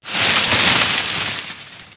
1 channel
leaves1.mp3